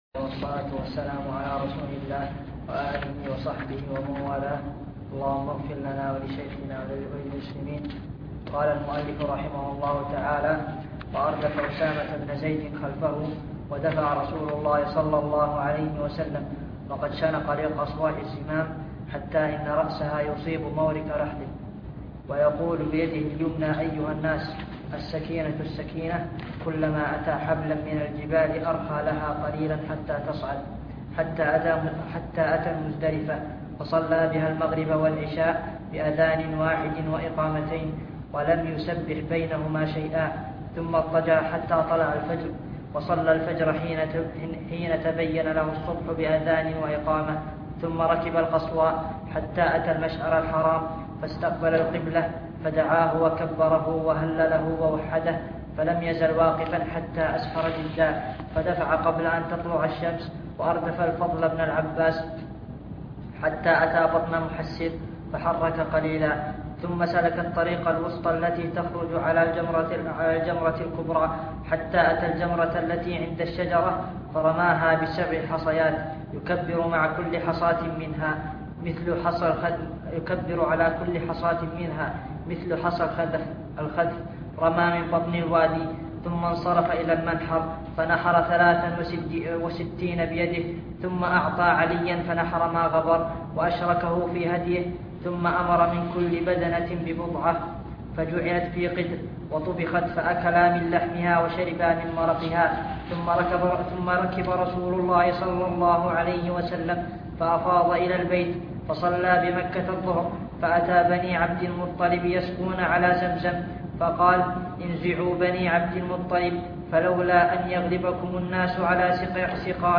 شرح كتاب الحج من منهج السالكين - الدرس الخامس